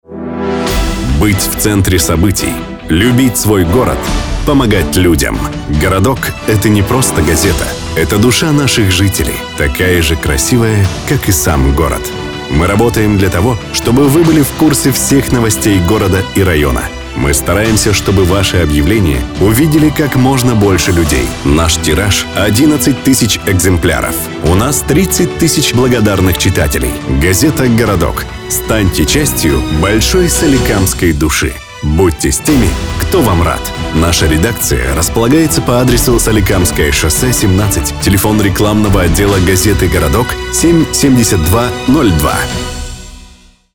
Текст ролика для радио (Городская газета)